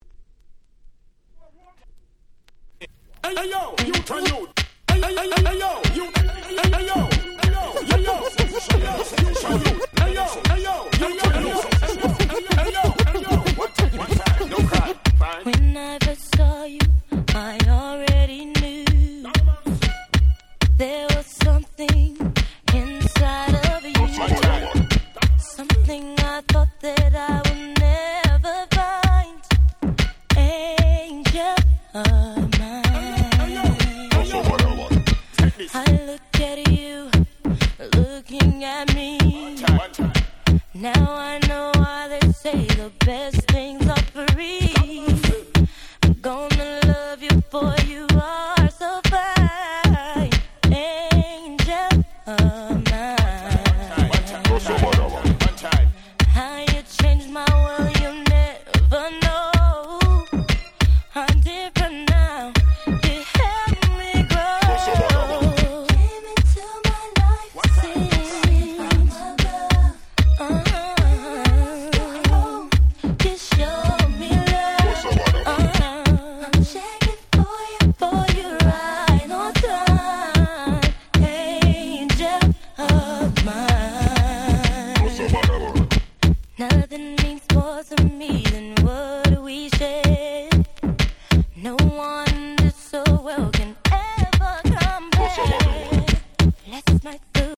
変にイジってなくてシンプルですごく使い易い！！
こういうR&BのDancehall Remixってめちゃ使えますよね〜！